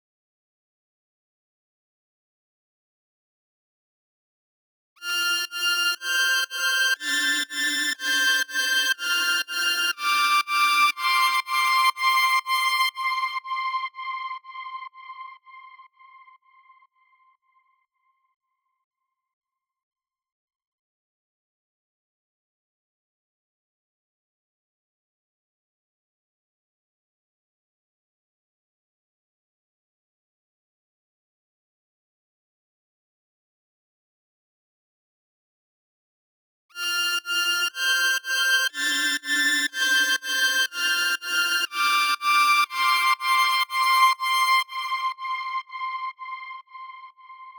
🔹 50 Premium Serum Presets crafted for melodic house, cinematic soundscapes, and deep emotional productions.
• Layered & Textured Sounds for that big cinematic feel
Preset Preview
RearView-Lights-0017-Instrument-LE-Petal-Drive.wav